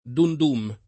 vai all'elenco alfabetico delle voci ingrandisci il carattere 100% rimpicciolisci il carattere stampa invia tramite posta elettronica codividi su Facebook Dumdum [ingl. d 9^ md 9 m ] top. (India) — di solito in gf. dum‑dum [it. dun d 2 m ] come nome di un tipo di pallottole